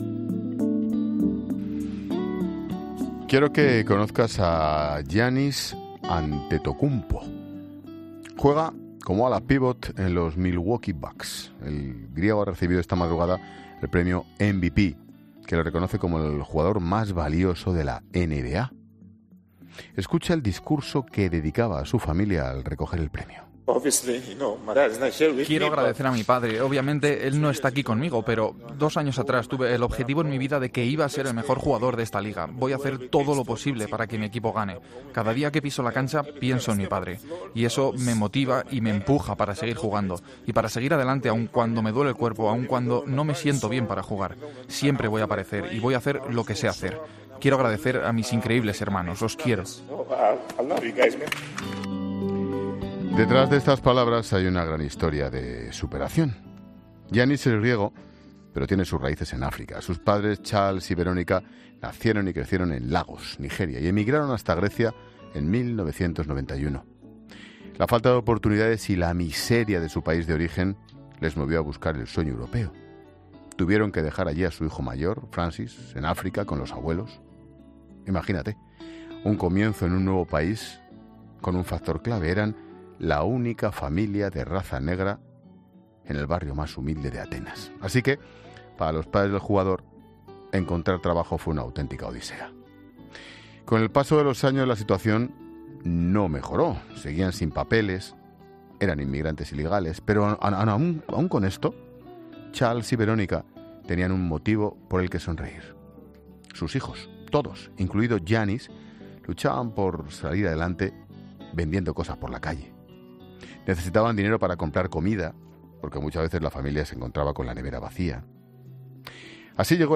El griego ha recibido esta madrugada el Premio MVP que lo reconoce como el jugador “más valioso” de la NBA. Escucha el discurso que dedicaba a su familia al recoger el premio.